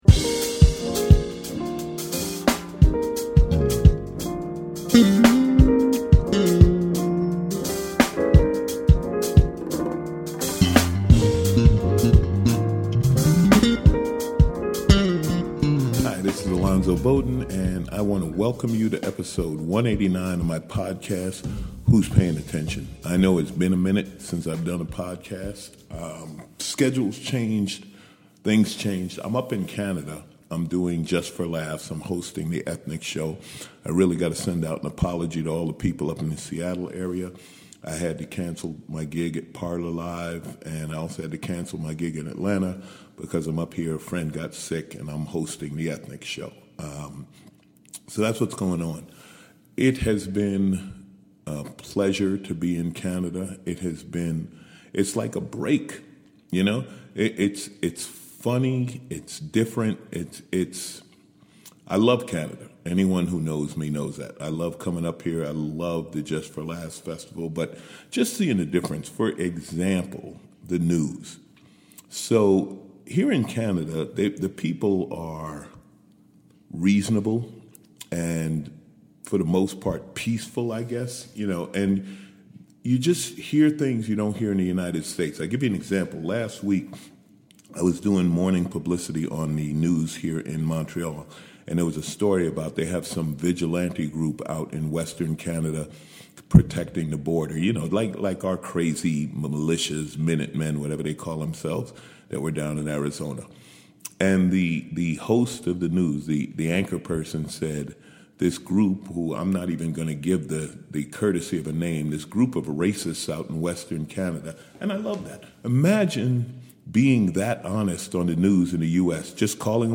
#189: Live From Montreal